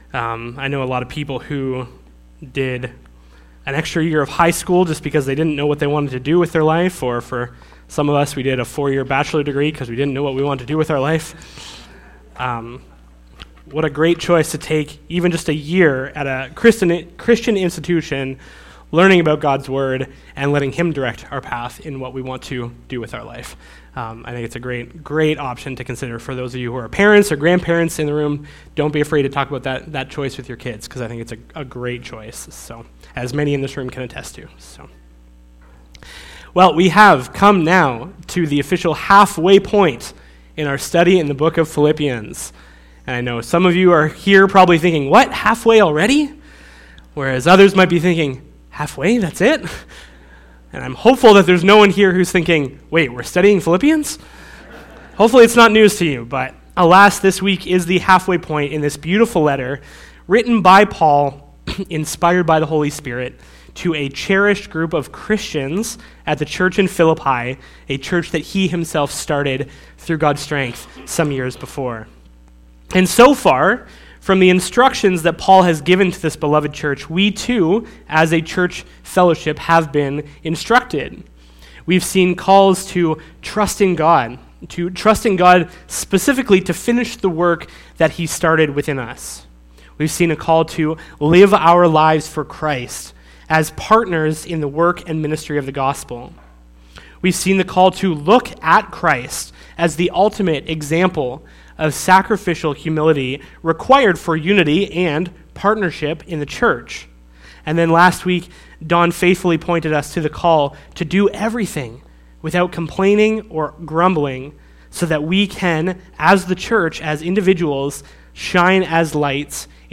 Sermon Audio and Video